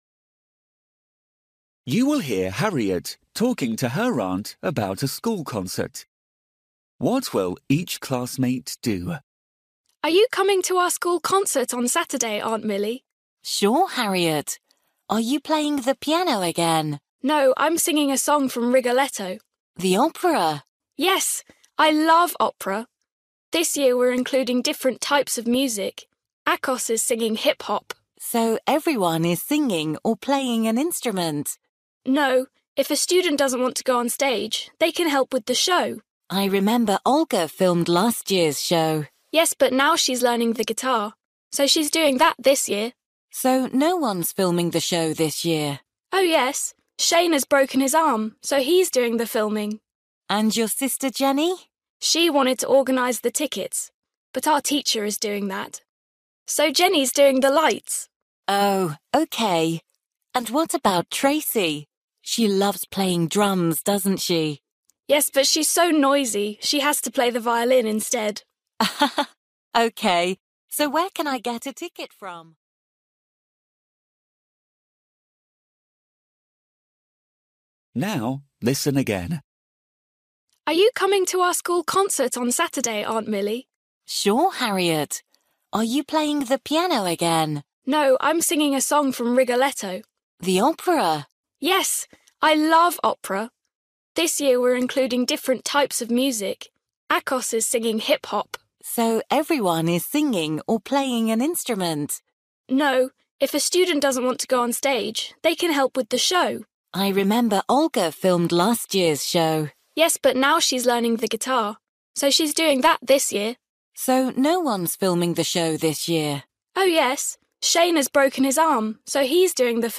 You will hear Harriet talking to her aunt about a school concert.